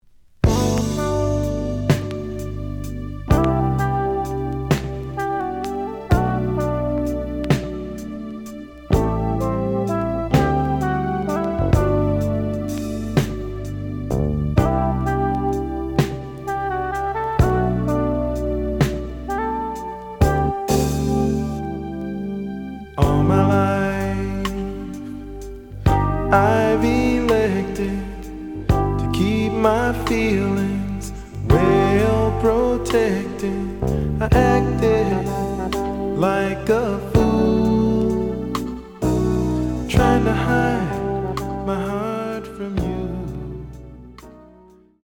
The audio sample is recorded from the actual item.
●Genre: Soul, 80's / 90's Soul
Some click noise on B side.